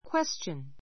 kwéstʃən